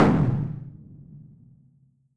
sounds_explosion_distant_04.ogg